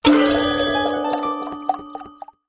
Здесь собраны необычные аудиозаписи: переливы волшебных зелий, таинственные всплески и другие мистические эффекты.
Магический звук использования эликсира в игре